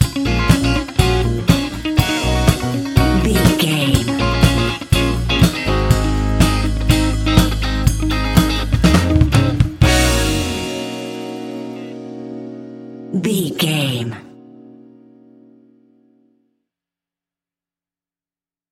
Ionian/Major
A♯
house
electro dance
synths
techno
trance
instrumentals